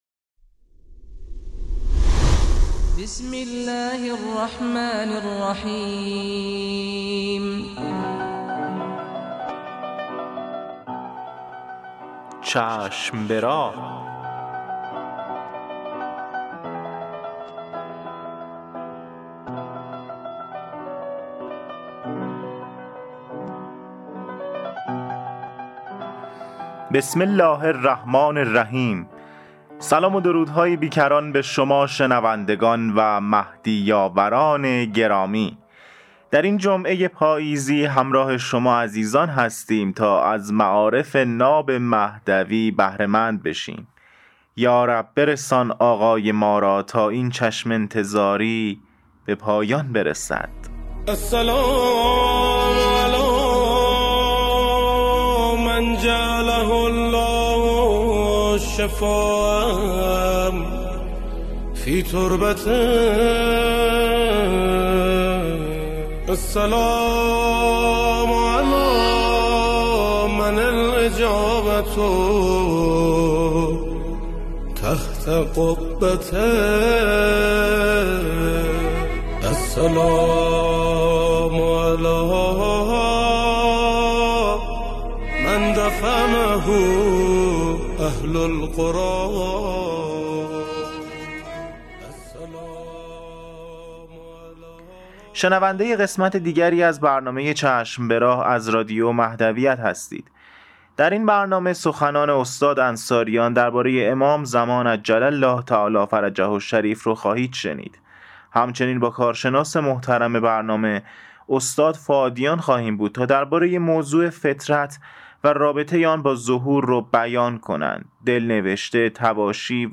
تواشیح مهدوی